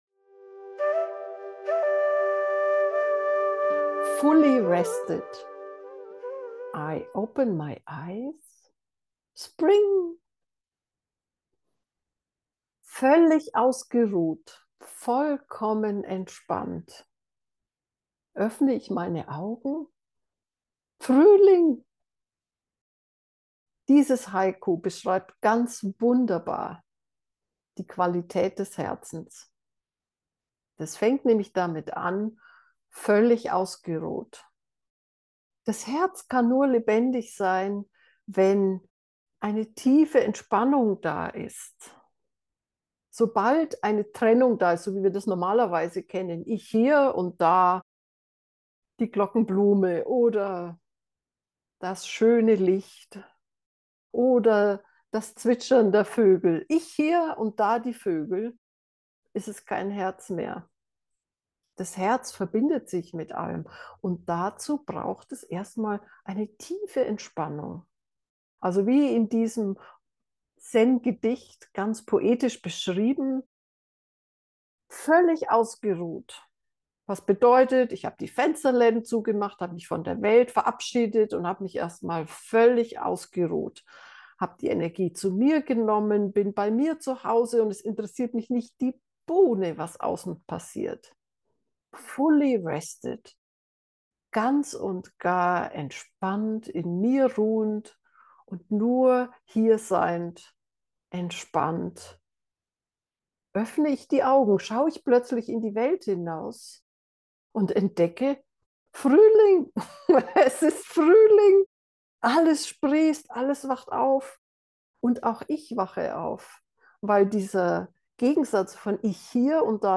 Auf eine 6-minütige Einleitung folgt eine 15-minütige, geführte Meditation.